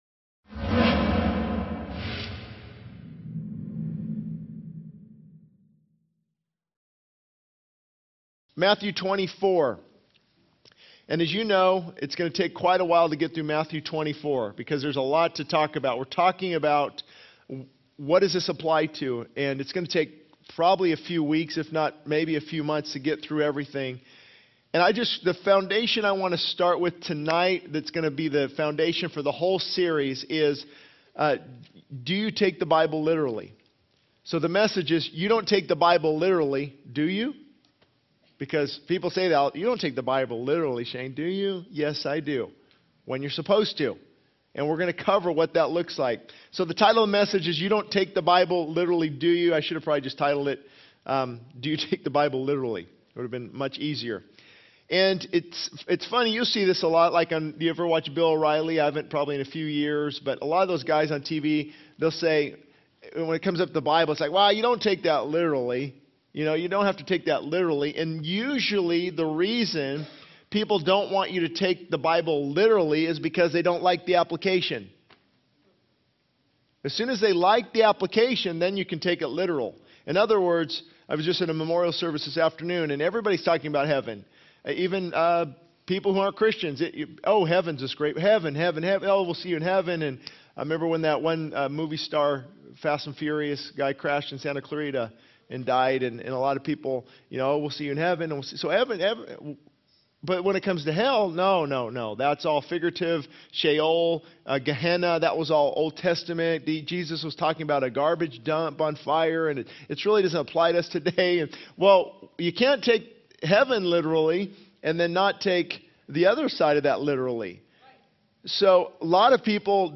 This sermon delves into the foundational question of whether to take the Bible literally, exploring the implications of literal interpretation and the need for consistency in applying God's Word. It addresses the potential deception that arises when people deviate from the truth of Scripture and emphasizes the importance of being prepared for Christ's return, urging believers to not be caught off guard by worldly distractions or unpreparedness.